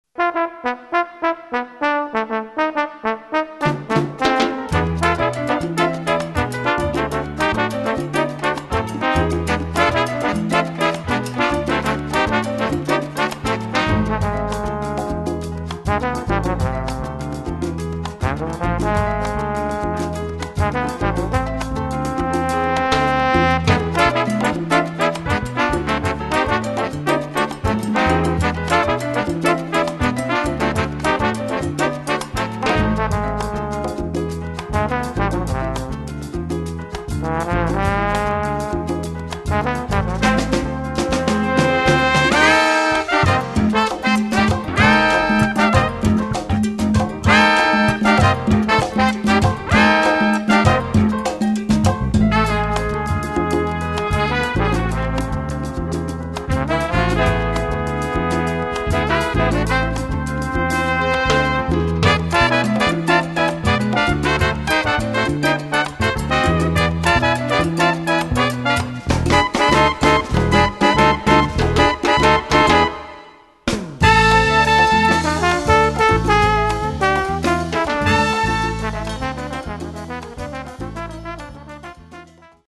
Category: combo
Style: mambo